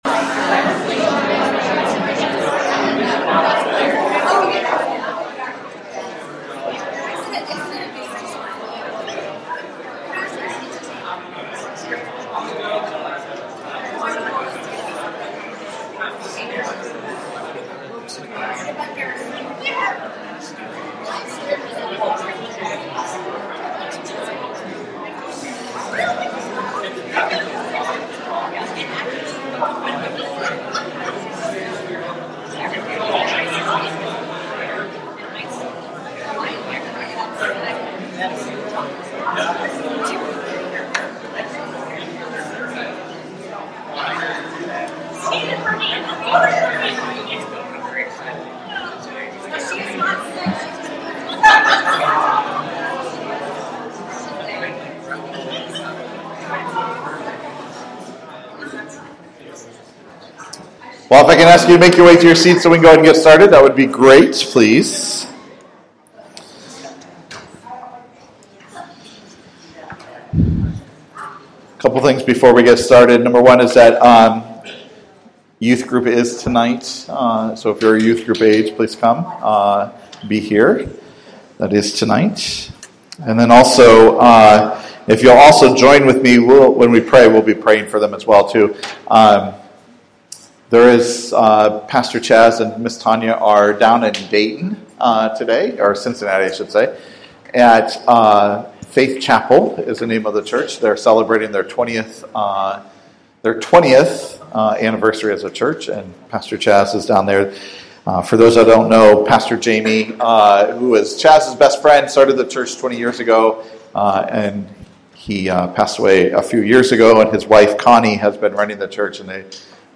Sermons by Passion Community Church